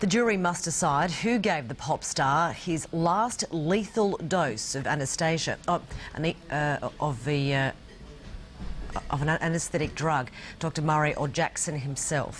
Tags: News blooper news news anchor blooper bloopers news fail news fails broadcaster fail